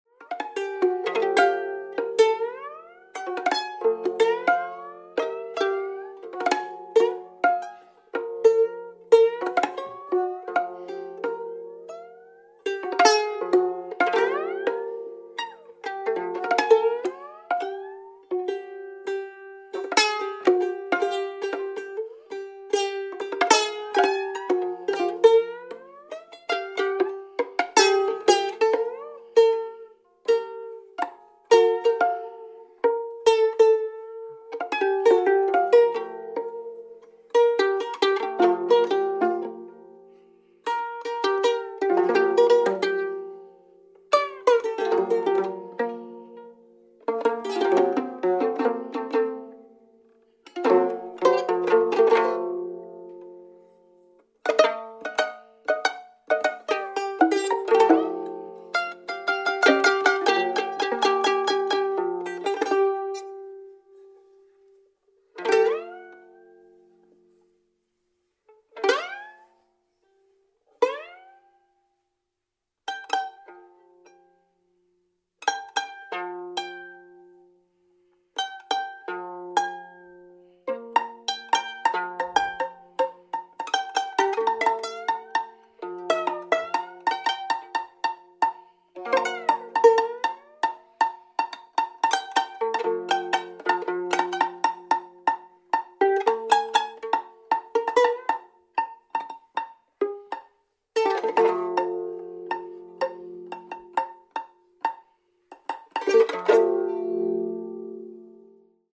violin duo